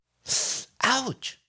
Voice_Ouch_1.wav